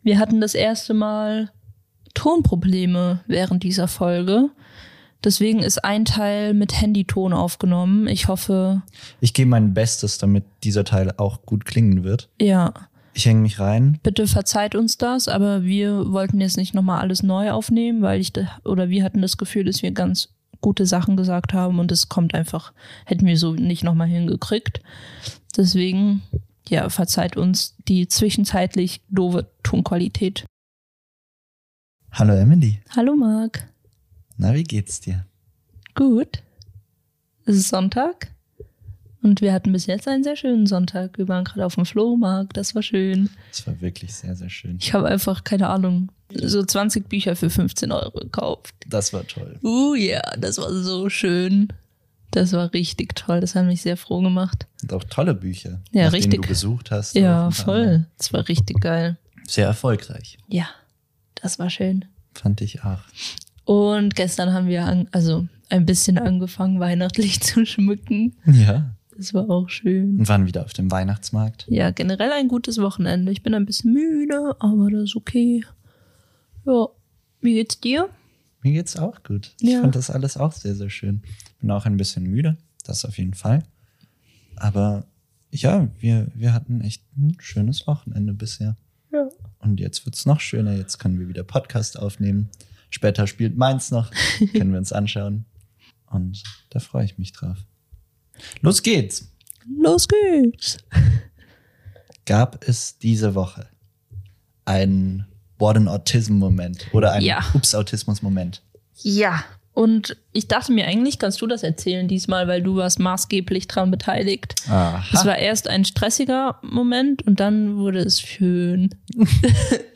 Wir hoffen, dass ihr die Folge trotz Tonprobleme genießen könnt und freuen uns wie immer über Kommentare, Bewertungen und Feedback Mehr